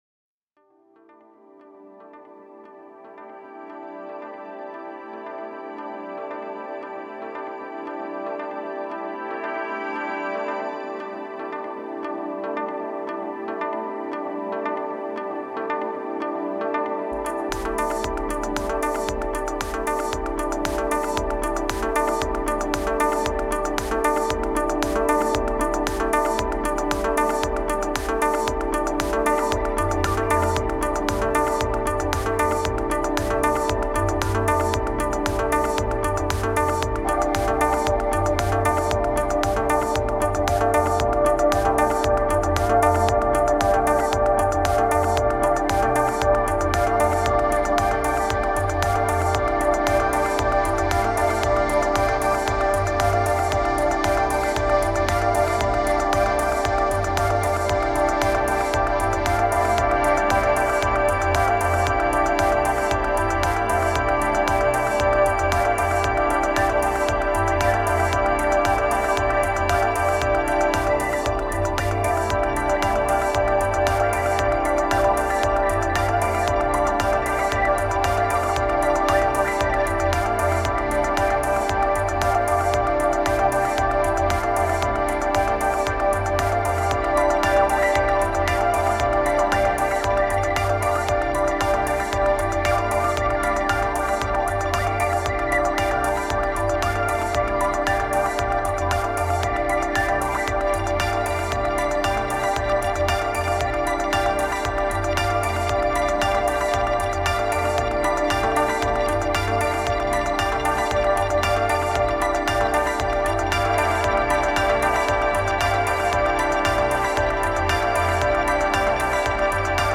Bad moods.
Feels wrong and depressed.